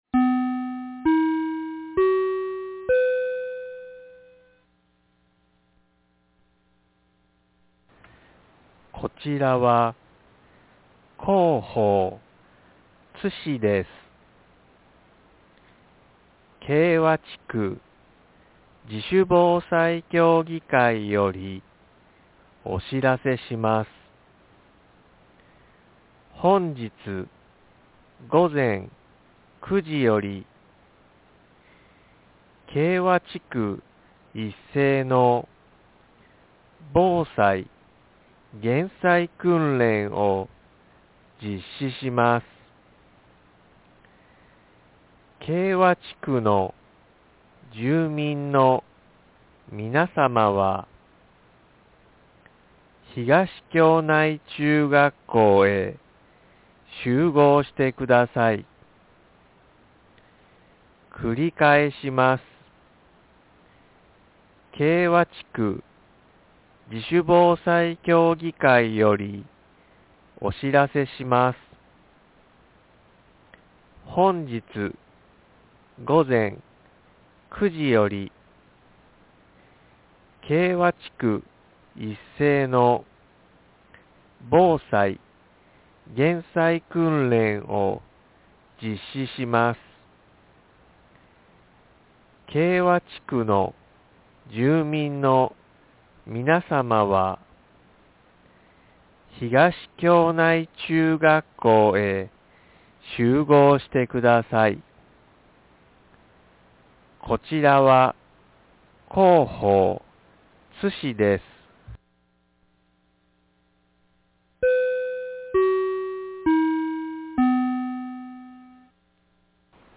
2024年11月30日 08時29分に、津市より敬和へ放送がありました。
放送音声